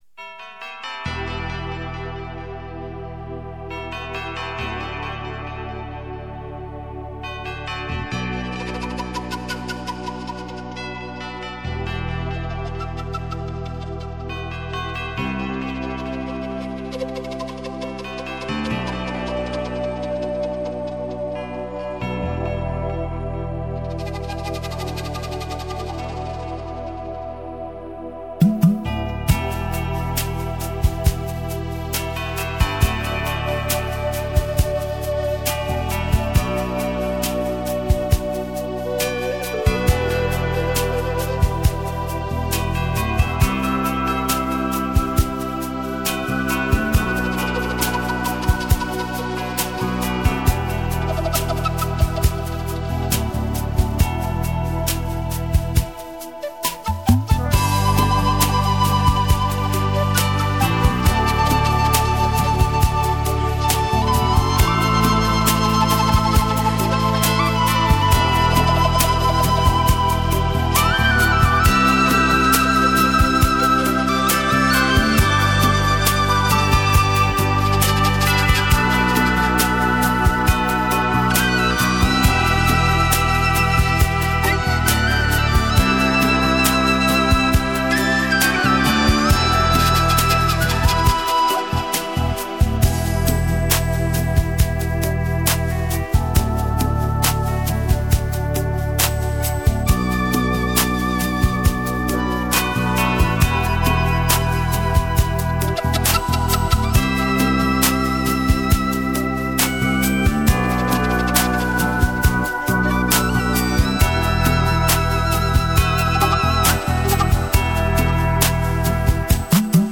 Музыкальное сопровождение